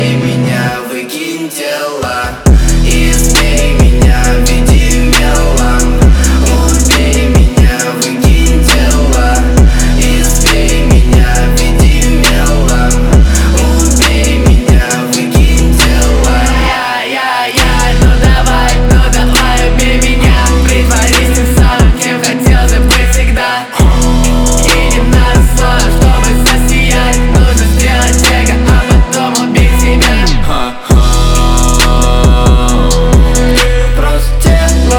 Жанр: Рэп и хип-хоп / Иностранный рэп и хип-хоп / Русские